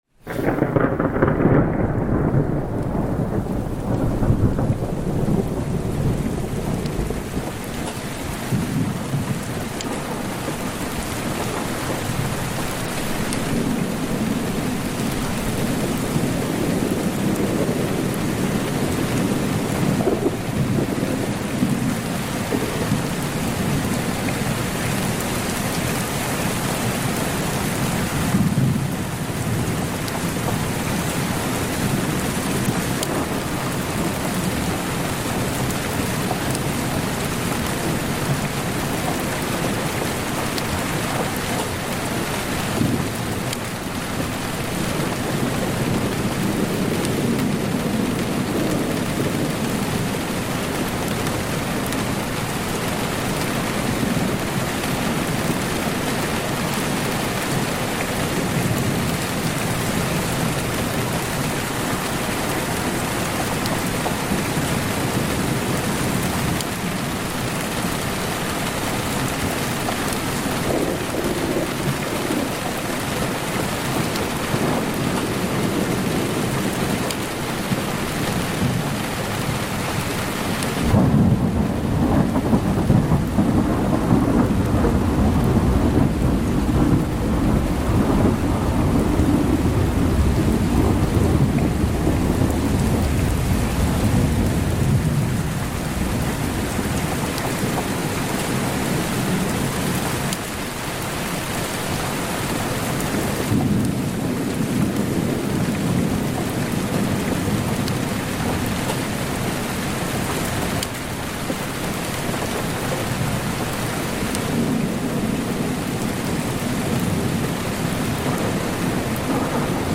Lluvia y Trueno en un Refugio Natural para Dormir sin Esfuerzo ni Desvelos
Lluvia Tranquila, Lluvia de Fondo, Sonido de Tormenta, Día Lluvioso, Lluvia Para Soñar